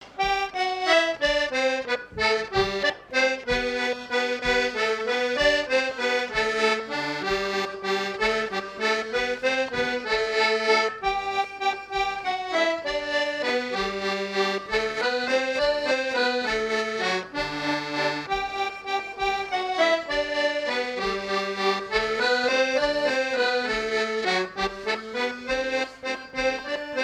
Valse
danse : valse
Fête de l'accordéon
Pièce musicale inédite